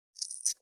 507大根の桂むきの音切る,包丁,厨房,台所,野菜切る,咀嚼音,ナイフ,調理音,
効果音厨房/台所/レストラン/kitchen食材